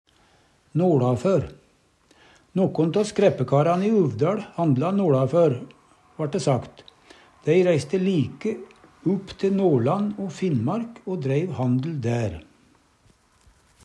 noLafør - Numedalsmål (en-US)